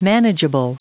Prononciation du mot manageable en anglais (fichier audio)
Prononciation du mot : manageable